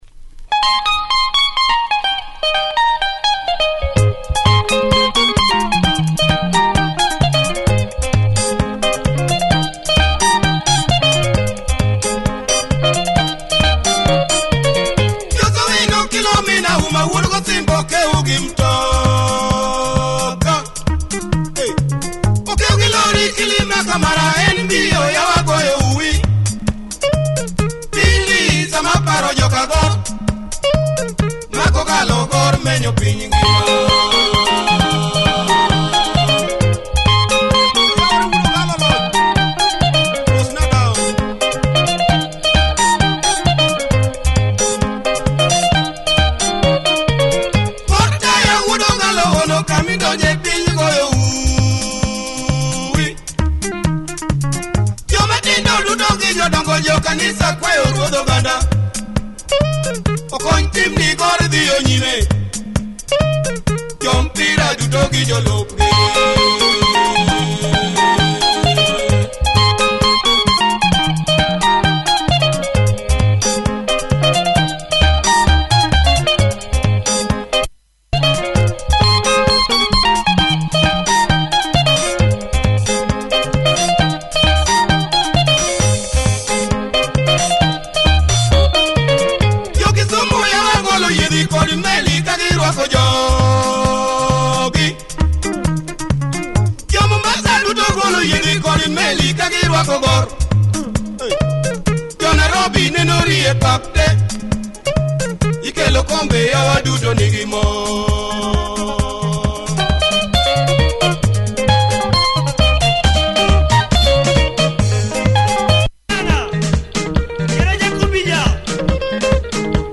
Nice Luo benga, good thumping on the b side!